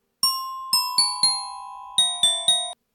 Guide des carillons